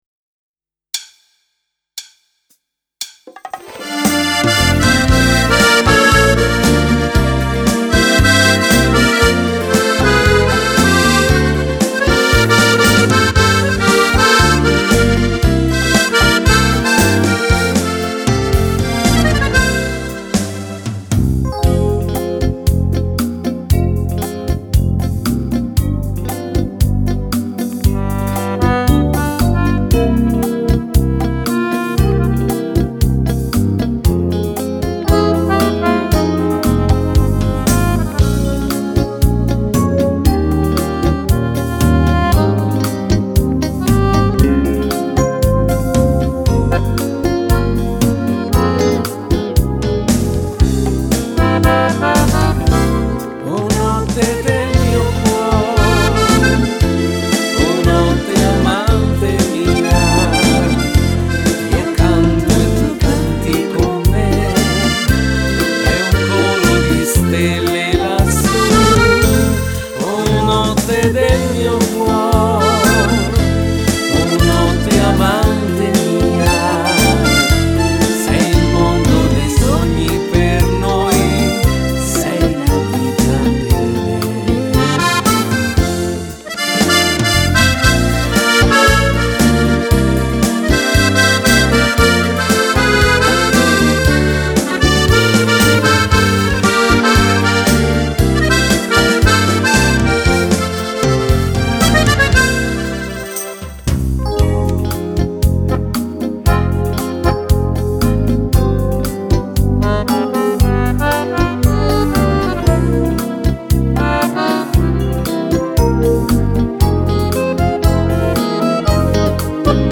Beguine
Uomo